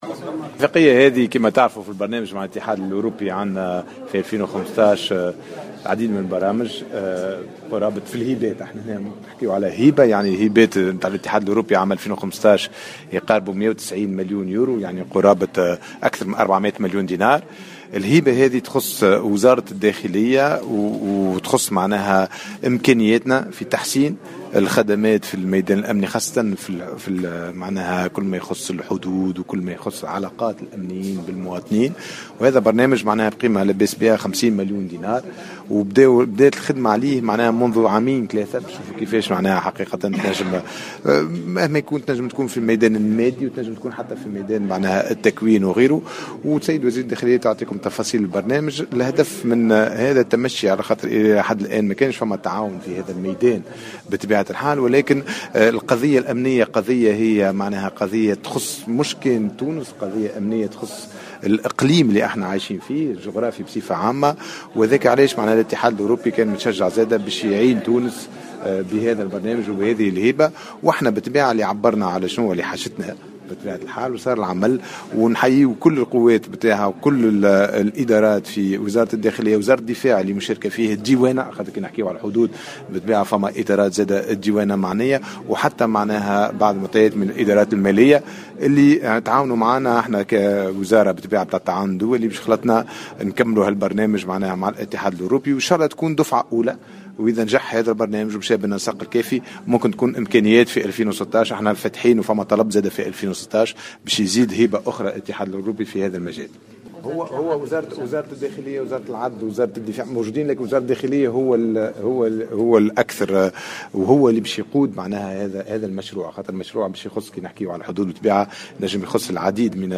وأكد ياسين ابراهيم لمراسل الجوهرة أف أم أن الاتحاد الاوربي سيمنح تونس هبات بقيمة 190 مليون أورو سنة 2015 ، ومن بينها الهبة التي تم التوقيع عليها اليوم بقيمة 50 مليون دينار والتي ستخصص لوزارة الداخلية لتحسين خدماتها الأمنية وخاصة فيما يتعلق بالحدود.